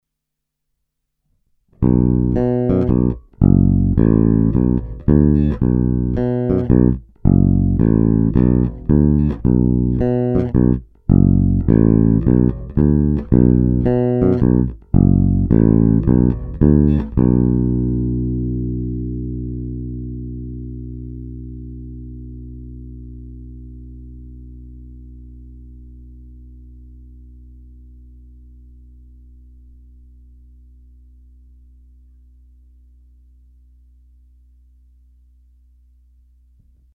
Ukázky jsou nahrány rovnou do zvukové karty a jen normalizovány.
Snímač u kobylky